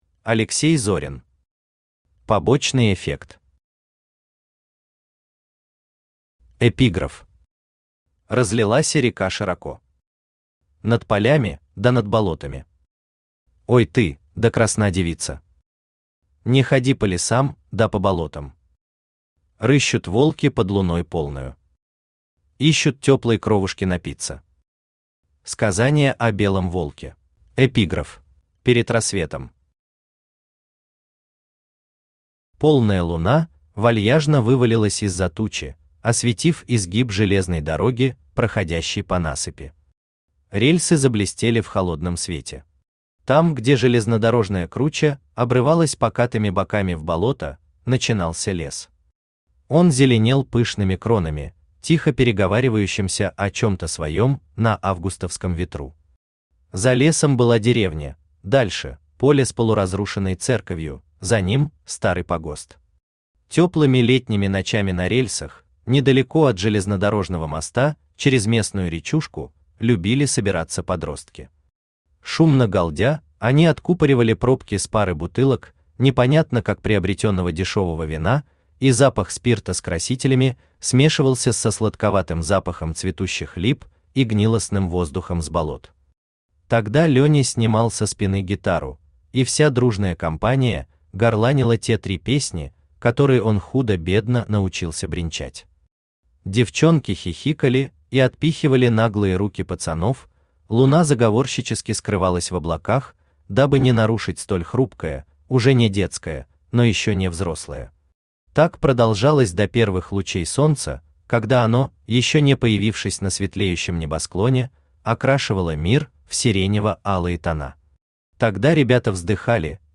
Читает: Авточтец ЛитРес
Аудиокнига «Побочный эффект».